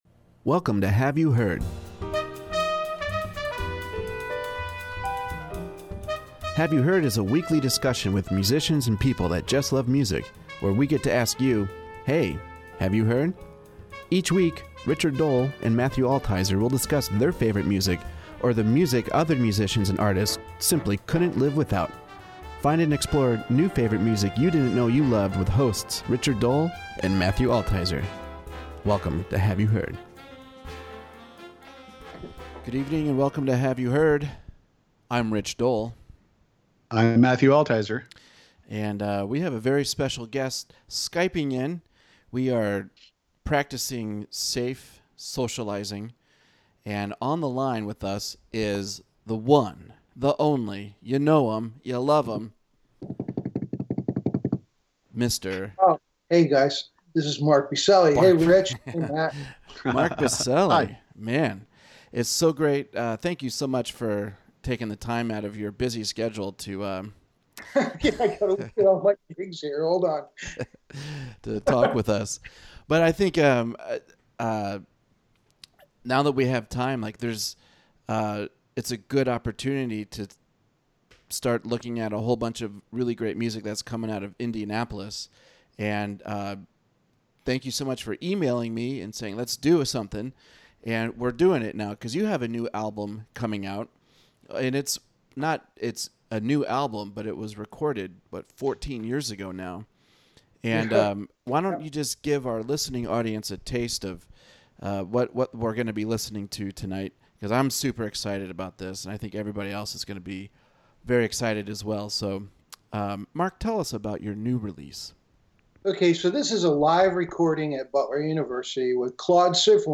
trumpet
piano